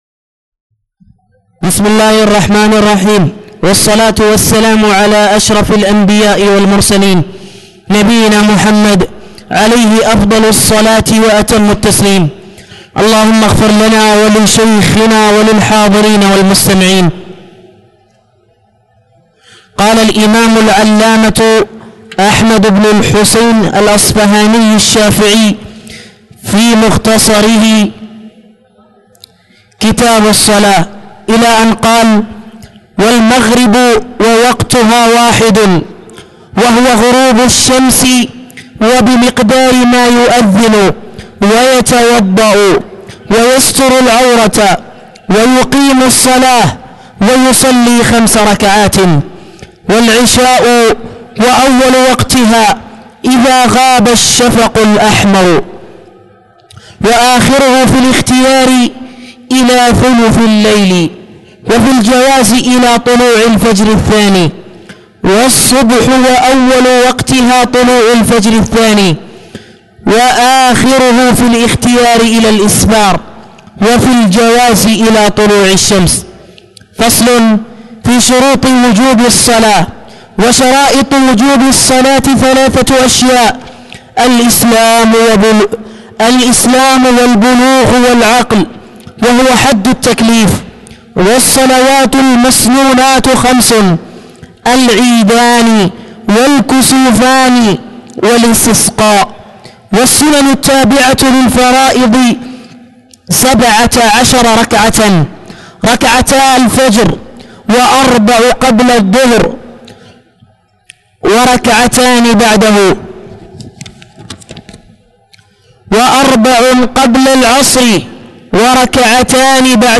تاريخ النشر ١٠ رمضان ١٤٣٧ هـ المكان: المسجد الحرام الشيخ